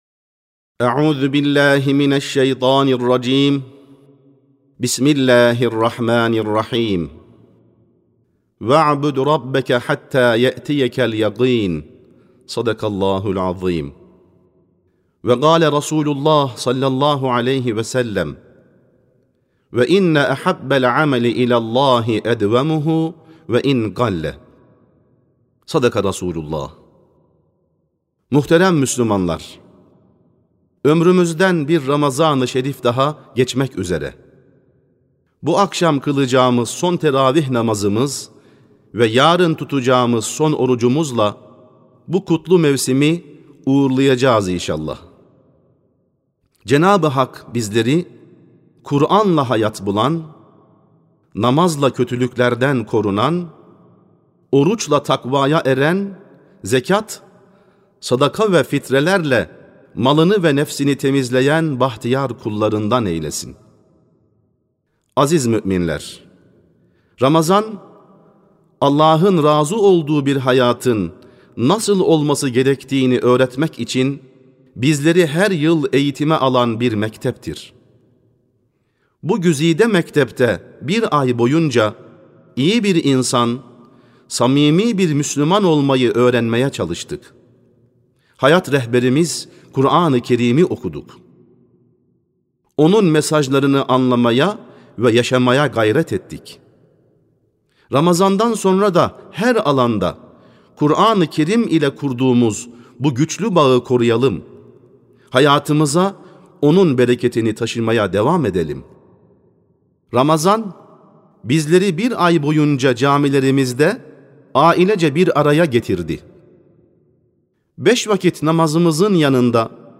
28.03.2025 Cuma Hutbesi: Ramazan'a Veda Ederken (Sesli Hutbe, Türkçe, İngilizce, Rusça, İspanyolca, İtalyanca, Arapça, Almanca, Fransızca)
Sesli Hutbe (Ramazan'a Veda Ederken).mp3